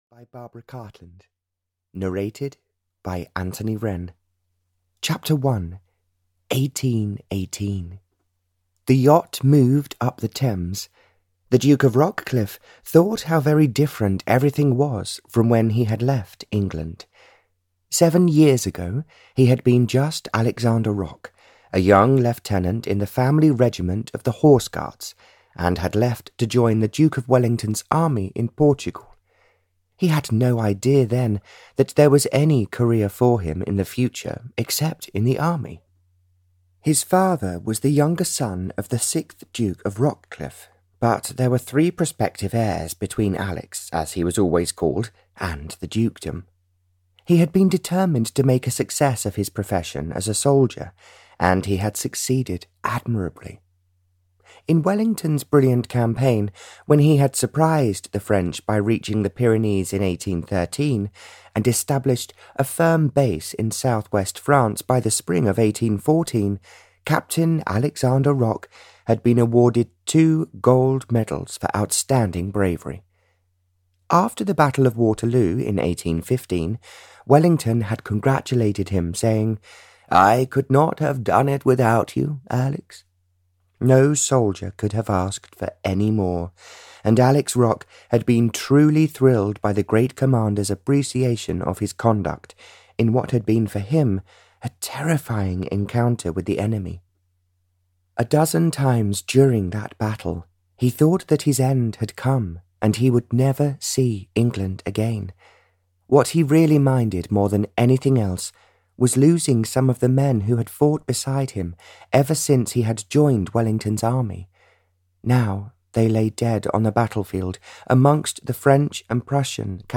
Audio knihaSaved by the Duke (Barbara Cartland's Pink Collection 123) (EN)
Ukázka z knihy